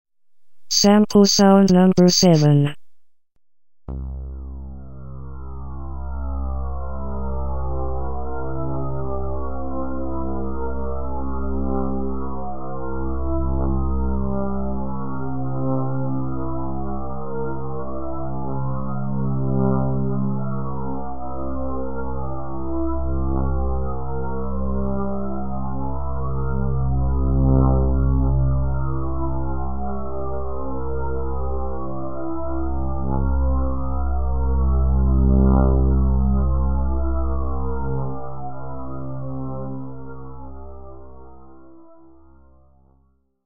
●鋸歯状波の多重生成（
そして4つの系列の唯一の違いは、それぞれに用いるピッチと位相を、 全て微妙に変えてあるところであり、この単純な差からうねるような独特の 響きの効果を得ている。